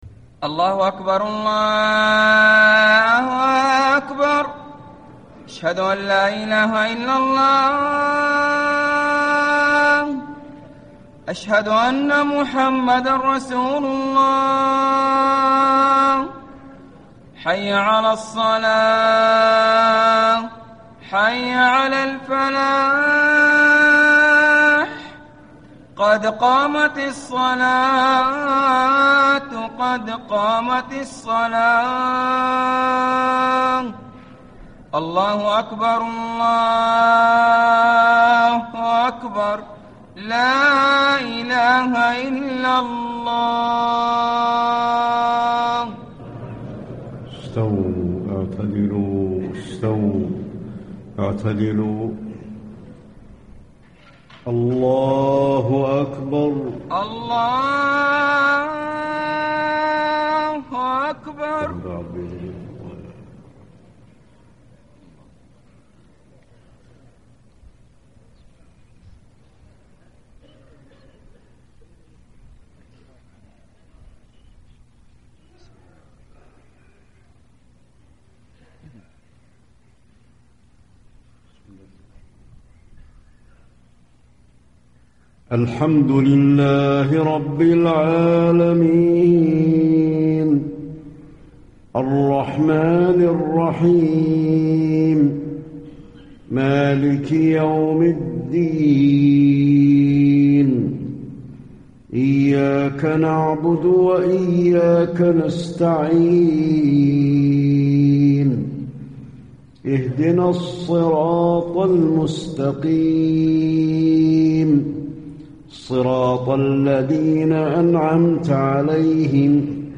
عشاء 4 شعبان ١٤٣٥ من سورة التكوير و الطارق > 1435 🕌 > الفروض - تلاوات الحرمين